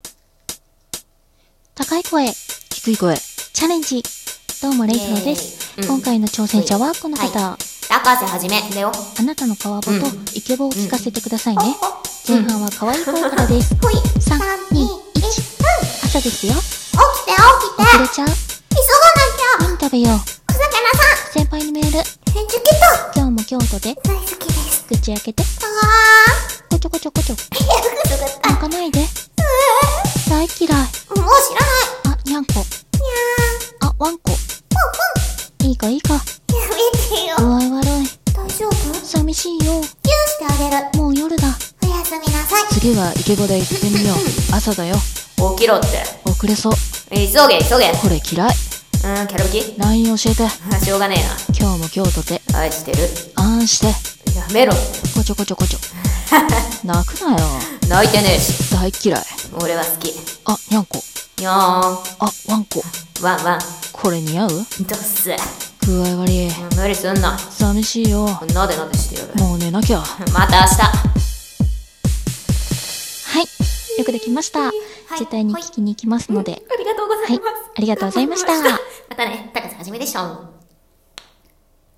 高い声・低い声チャレンジ‼